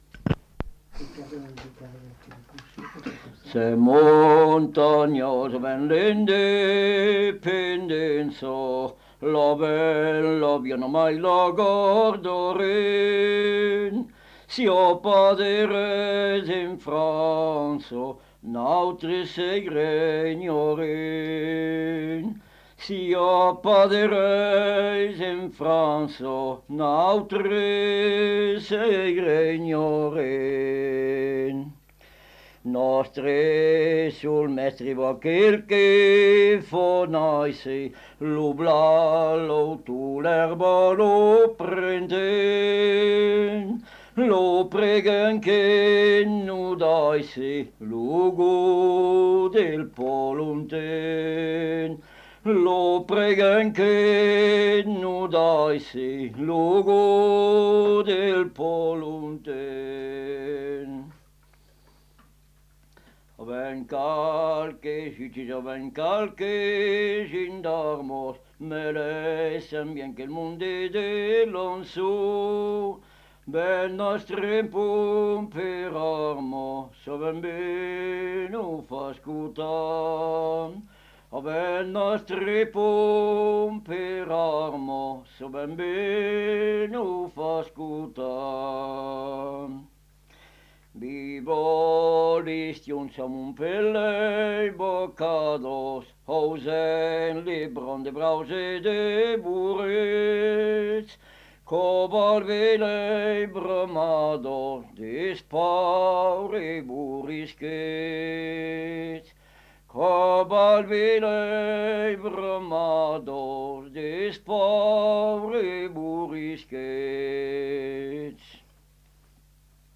Aire culturelle : Viadène
Genre : chant
Effectif : 1
Type de voix : voix d'homme
Production du son : chanté
Classification : chanson identitaire
Notes consultables : Coupé avant la fin (fin de la face).